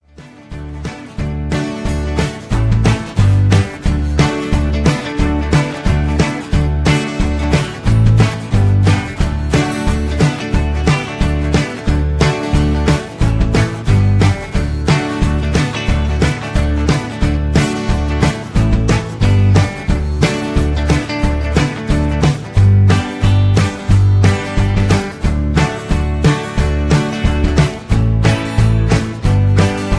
backing tracks
rock and roll, country rock, classic rock